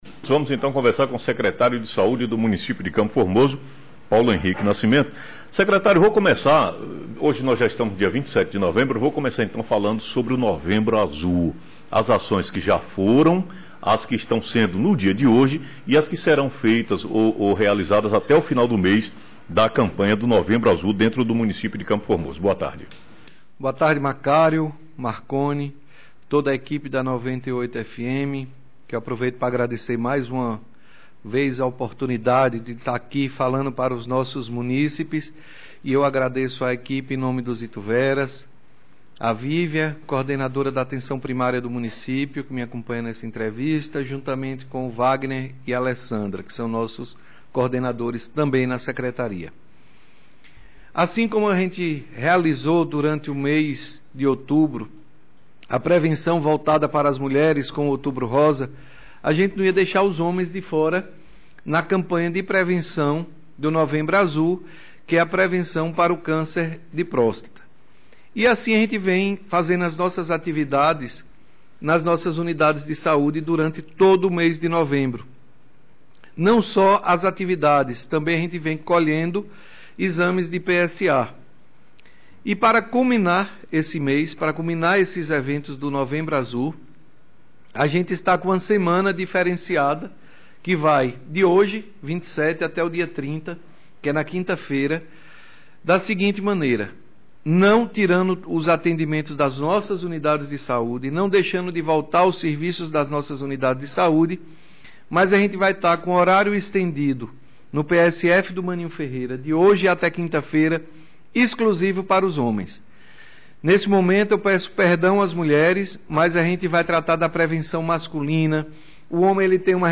Entrevista ao vivo com o secretário de saúde do municipio de CFormoso, Paulo Henrique Nascimento